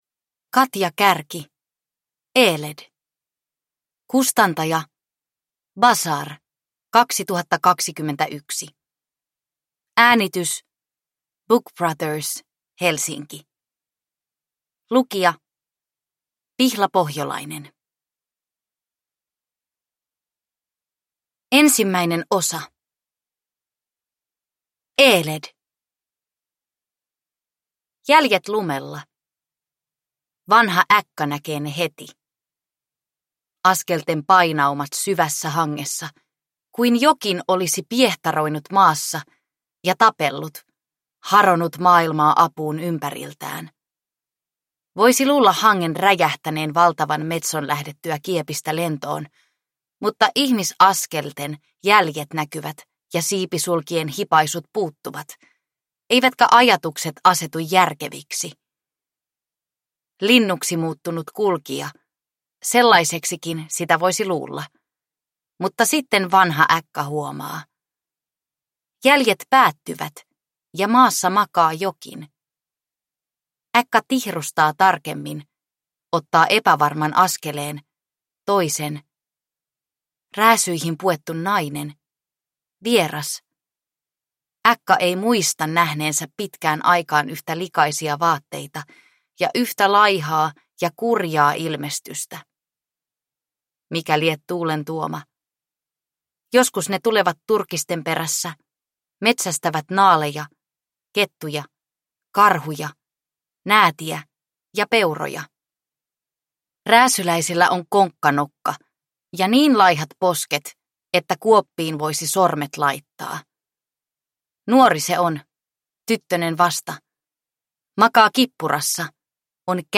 Eeled – Ljudbok – Laddas ner